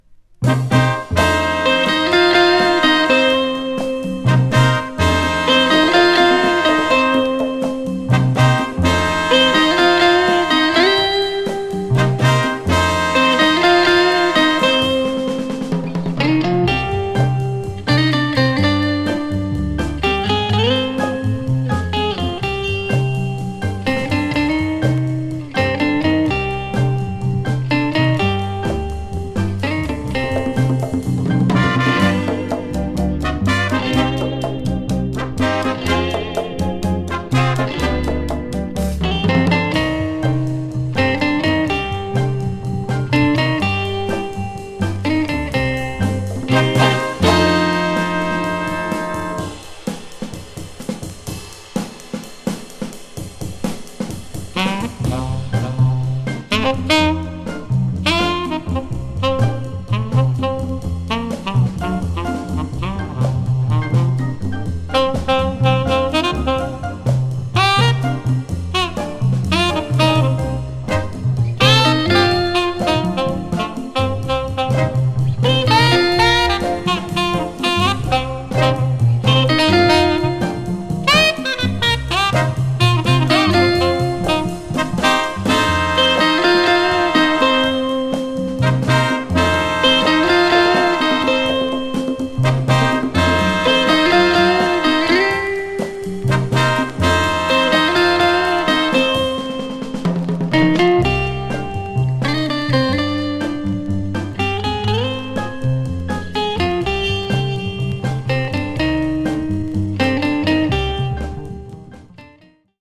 日本の伝統的な曲を取り上げた好作です。
秀逸なアレンジと演奏で多彩に聴かせます。